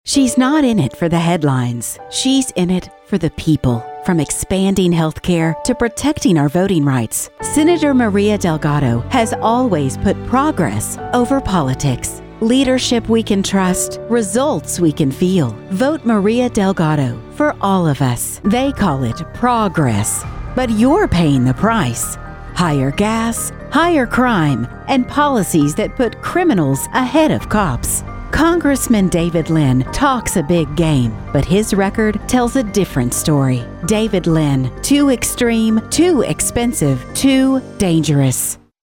Female Voice Over, Dan Wachs Talent Agency.
Female Democratic Voices
All with excellent home studios and paid Source Connect.